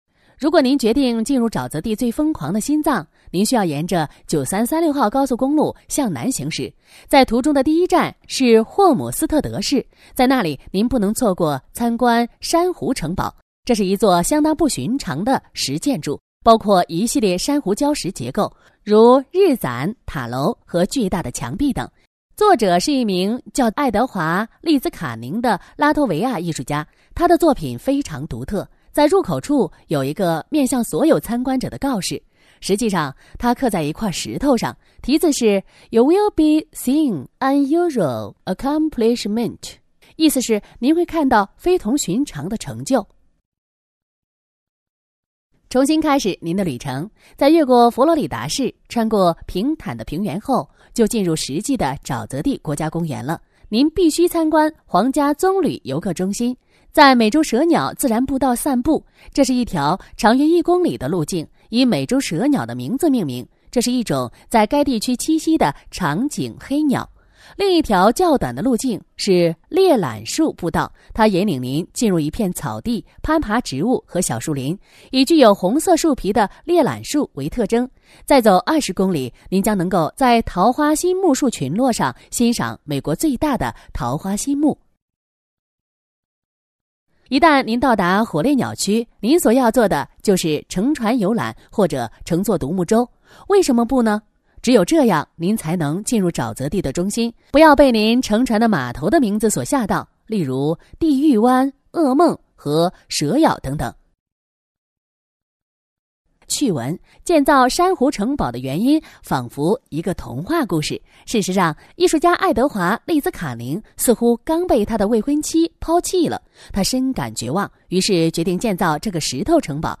Chinese_Female_001VoiceArtist_40Hours_High_Quality_Voice_Dataset
Tour Guide Style Sample.wav